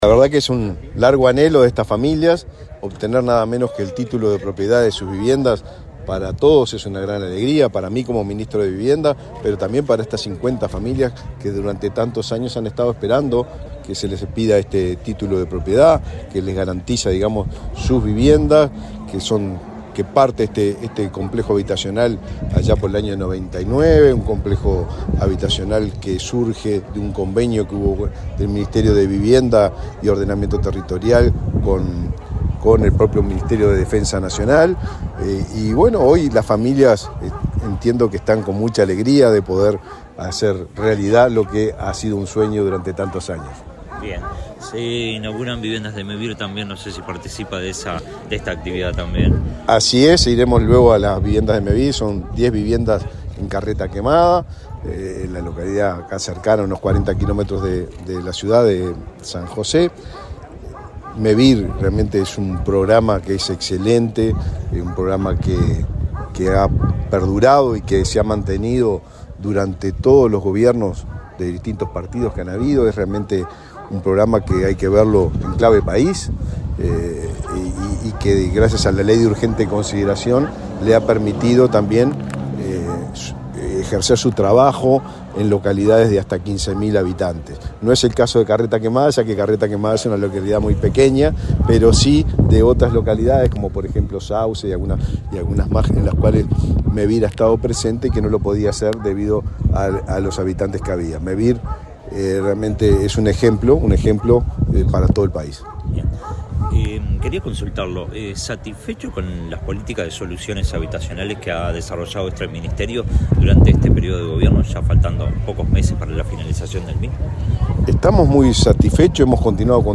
Declaraciones del ministro de Vivienda, Raúl Lozano
Declaraciones del ministro de Vivienda, Raúl Lozano 08/10/2024 Compartir Facebook X Copiar enlace WhatsApp LinkedIn El ministro de Vivienda, Raúl Lozano, dialogó con la prensa en San José, donde entregó los títulos de propiedad de las viviendas a 50 familias del complejo habitacional Dardo Carsin, en la capital de ese departamento.